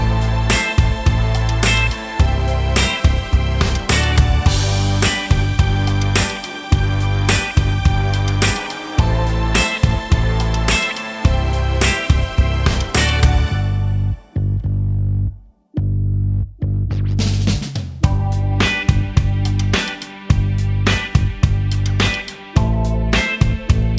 Pop (2020s) 3:38 Buy £1.50